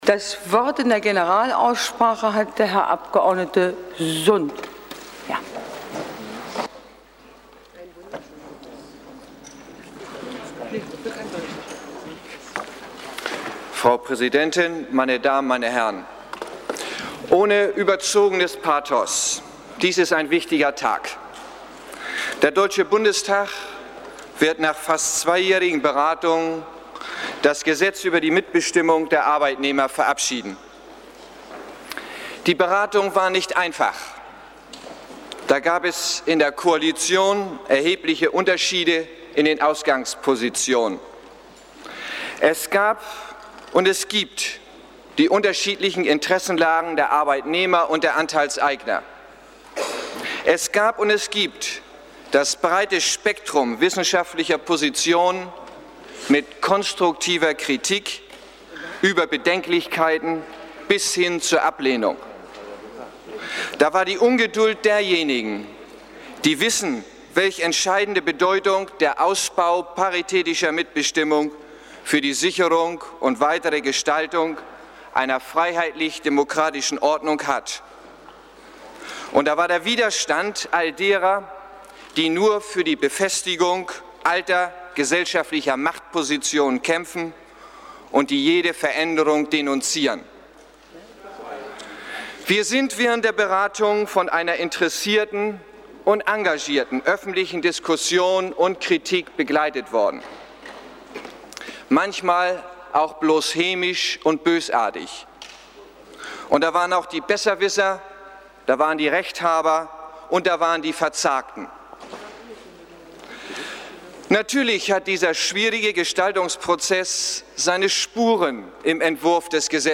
Rede des Abgeordneten Olaf Sund (SPD) am 18. März 1976, mpeg, 55 MB, Link öffnet ein neues Fenster